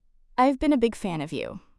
「I’ve been」（アイブ・ビーン）は、「アイビン」と聞こえたんじゃないかと思います。